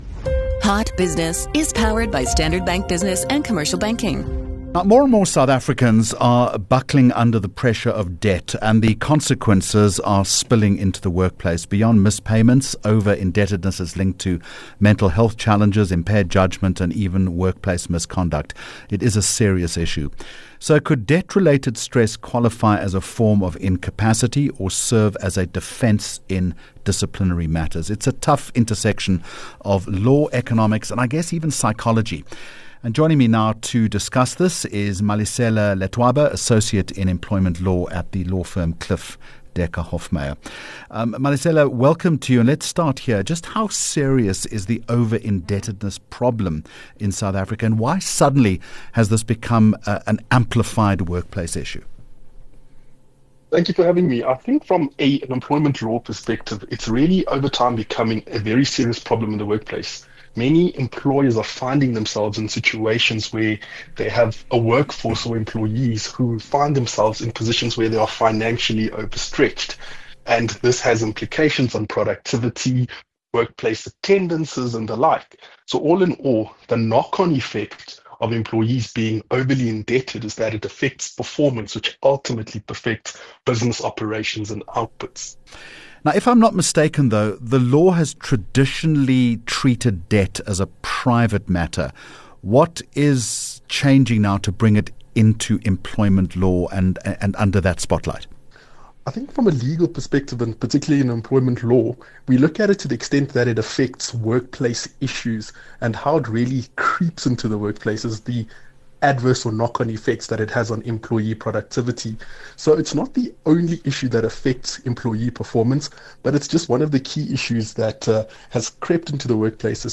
2 Jul Hot Business Interview